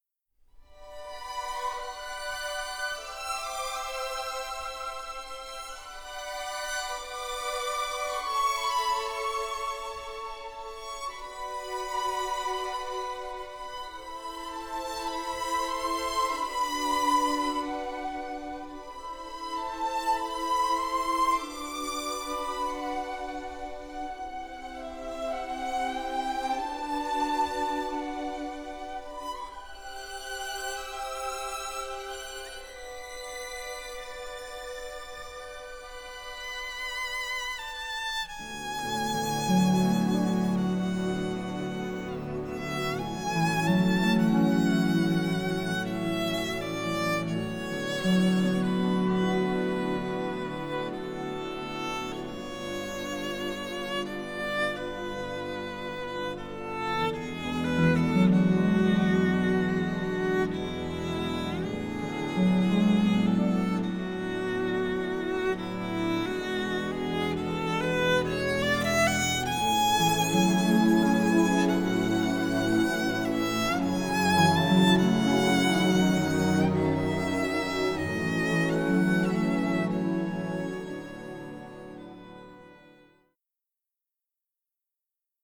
Both scores were recorded in January 2023